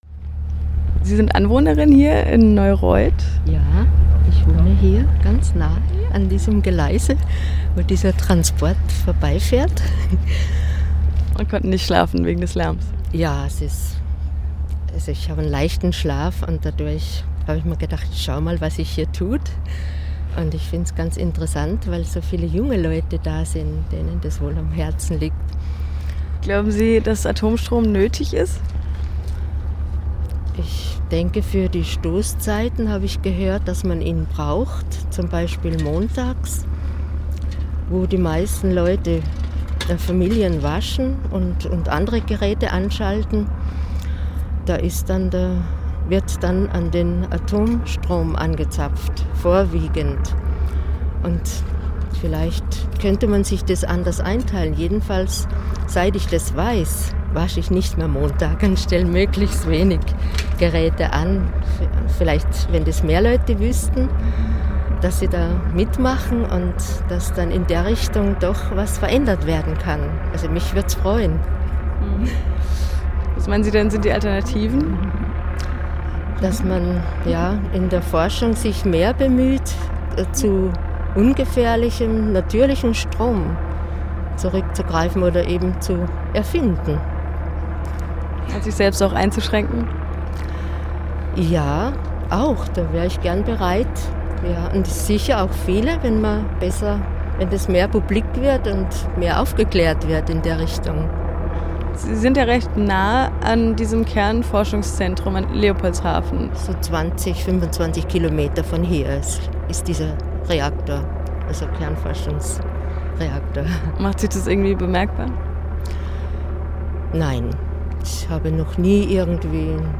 Castortransport von Karlsruhe nach Lubmin - Gespräch mit einer Anwohnerin in Karlsruhe
Am Rande der Nachttanzblockade, die vom 15. auf den 16. Februar in Karlsruhe stattfand, sprachen RDL mit einer Anwohnerin über Atomkraft, das ehemalige Kernforschungszentrum in Karlsruhe und die Proteste.